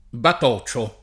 batocchio [bat0kkLo] (antiq. battocchio [batt0kkLo]) s. m.; pl. -chi — sim. i cogn. Battocchi, Battocchio batocio [
bato] (pl. -ci), in forma dialettale (ven.), il bastone d’Arlecchino o di Brighella